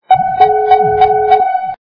При прослушивании Звук - Гидролокатор качество понижено и присутствуют гудки.
Звук Звук - Гидролокатор